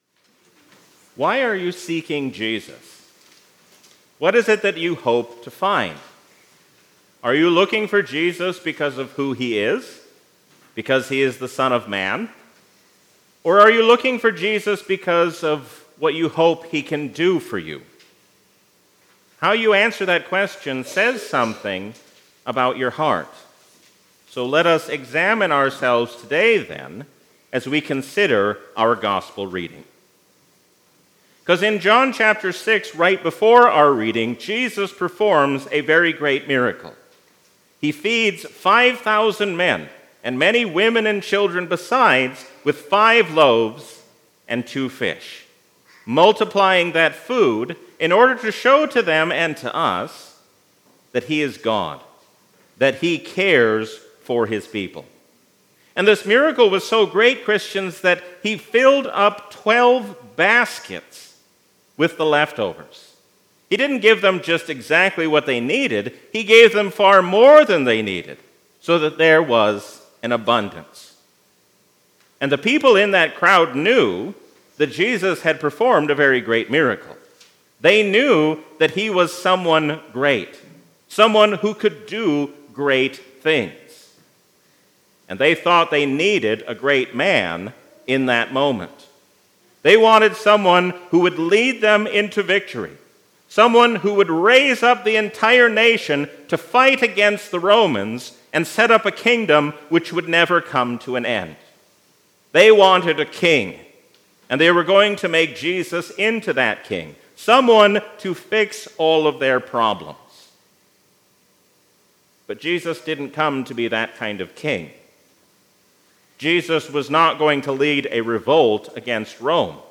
A sermon from the season "Lent 2023." Jesus teaches us what it means to seek after the will of God, even as we pray for things to be taken away.